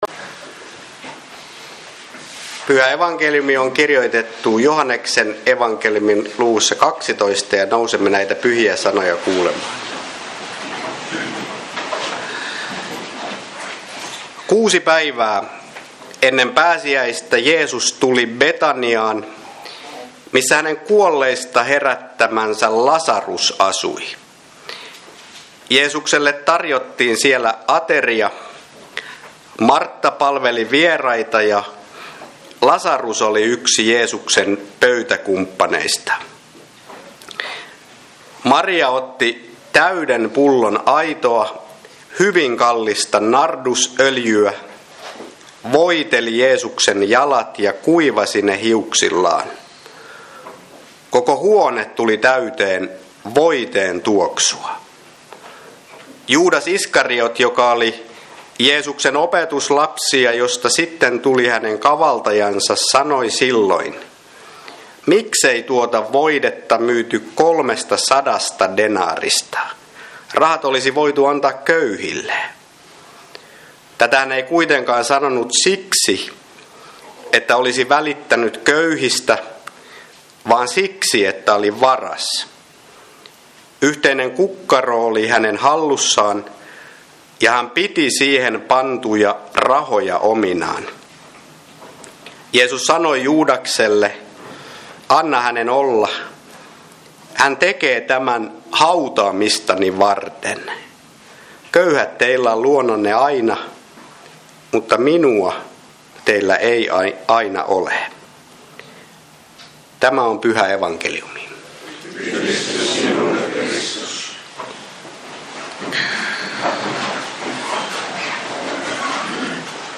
Kokoelmat: Seinäjoen Hyvän Paimenen kappelin saarnat